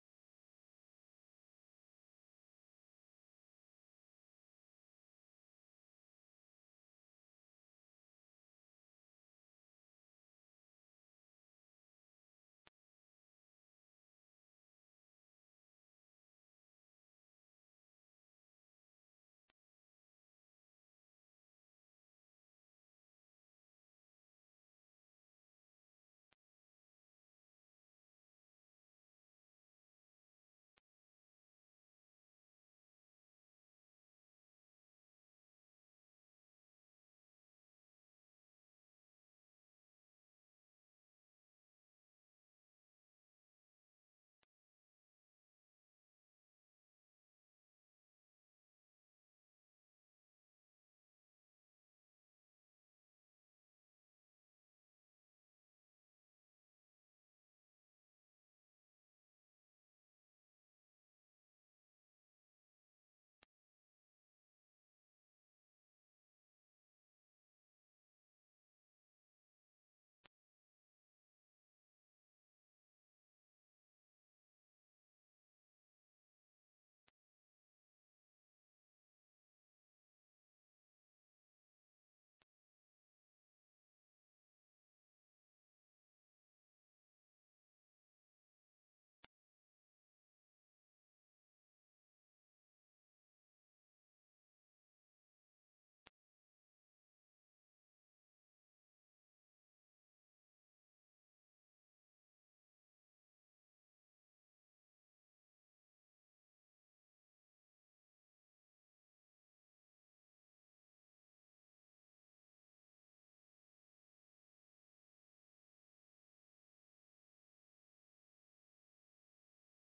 et Orchestre dir.